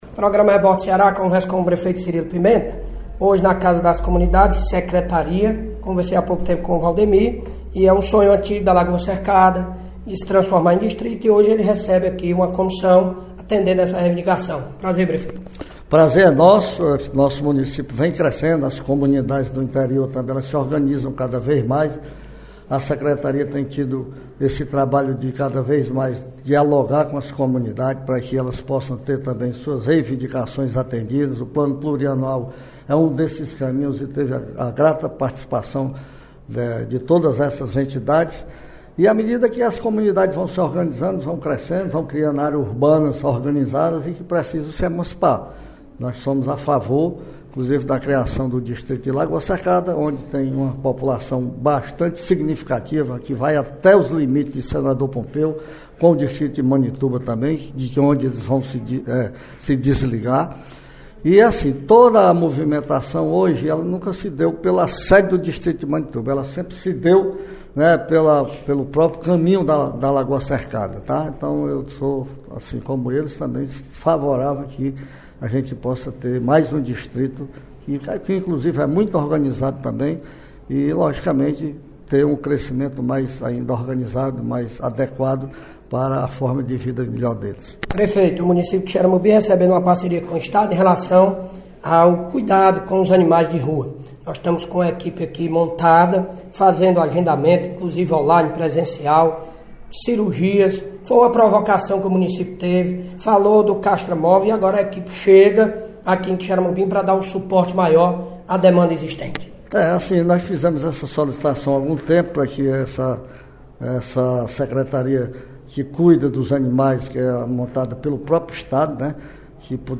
O prefeito de Quixeramobim, Cirilo Pimenta (PSB), afirmou em entrevista à Rádio Campo Maior que a gestão municipal pretende articular junto ao Governo do Estado a implantação de iluminação e a duplicação da CE-060, no trecho que liga a Superintendência de Obras Públicas (SOP), no Conjunto Esperança, ao Hospital Regional do Sertão Central (HRSC).